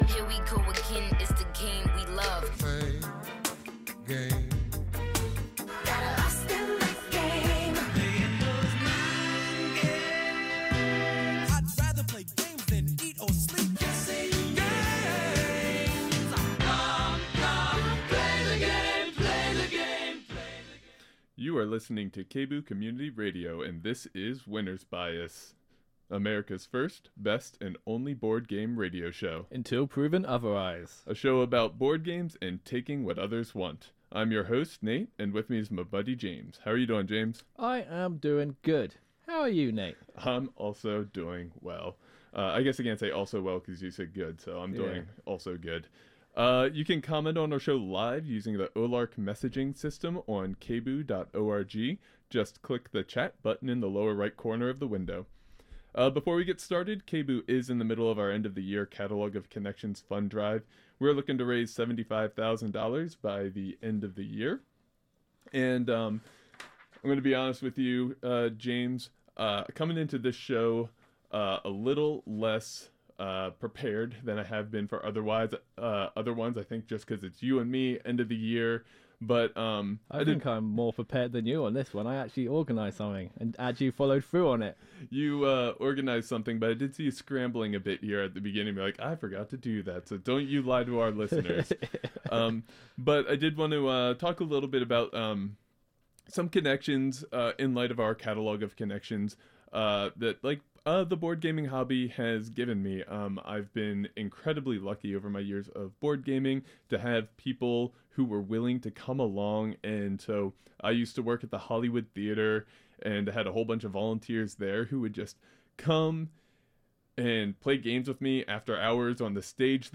Board Game Radio Show